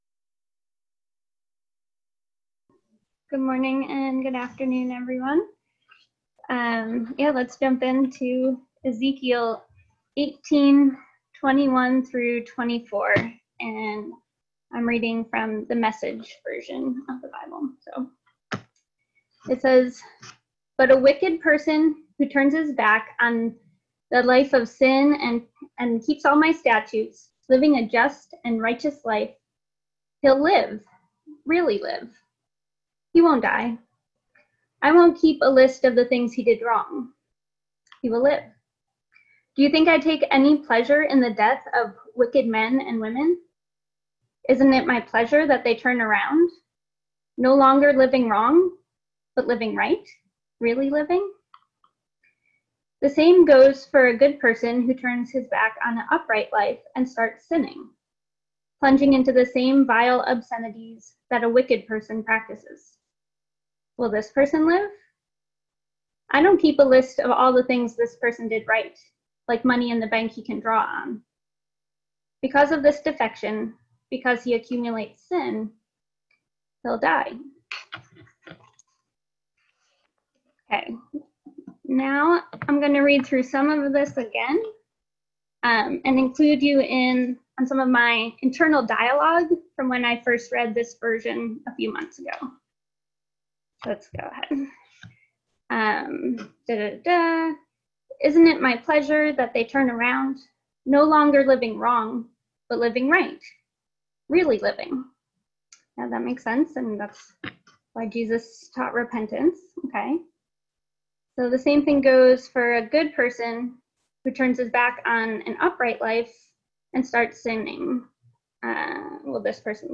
Listen to the most recent message from Sunday worship at Berkeley Friends Church, “Spiritually Penniless.”